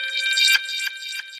Category: Messages Ringtones